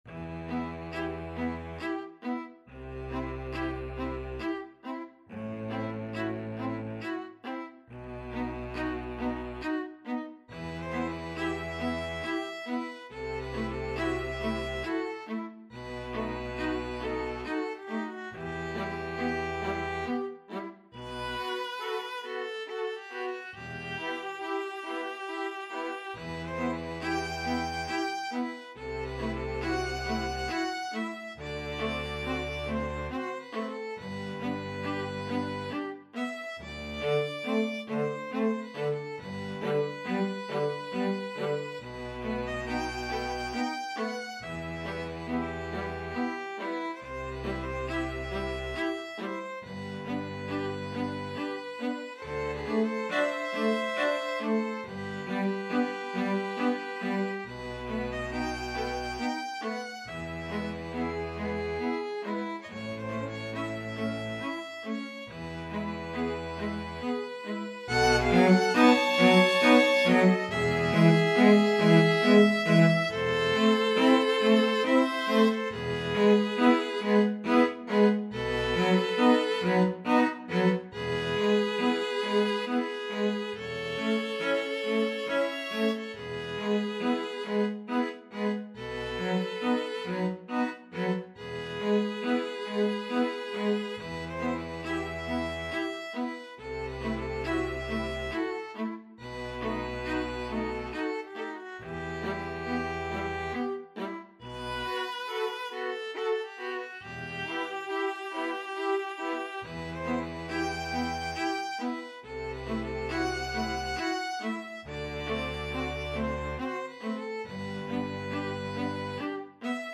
Classical String Ensemble
Violin 1 Violin 2 Viola Cello Double Bass